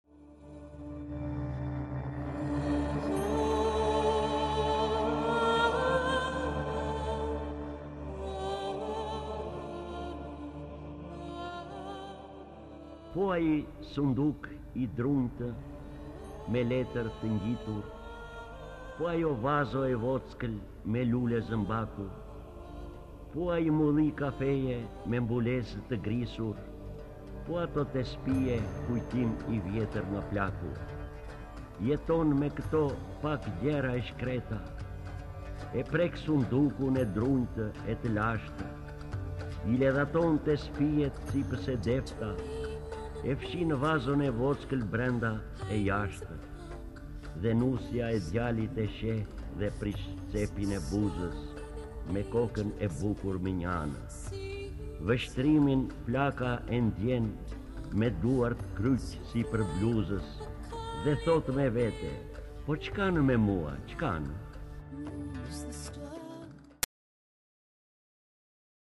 D. AGOLLI - NOSTALGJIA E PLAKËS Lexuar nga D. Agolli KTHEHU...